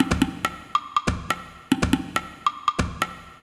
140_perc_1.wav